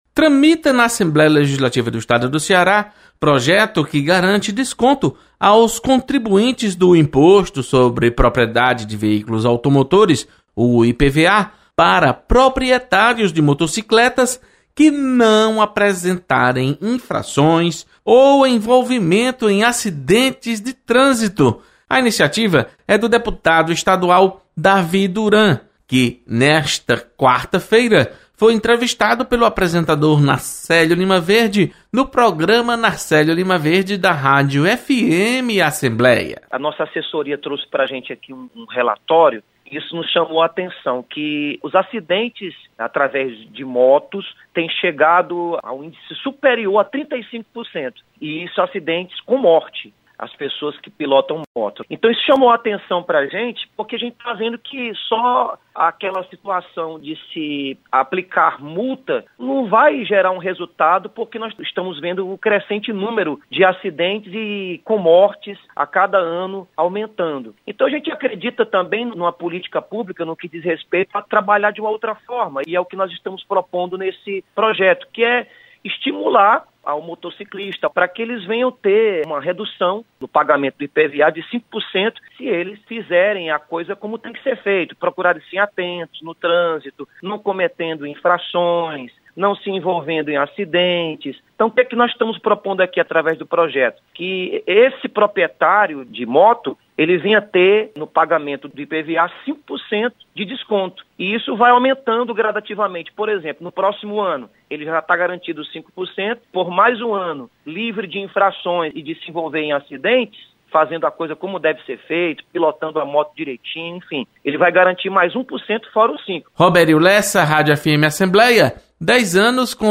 Plenário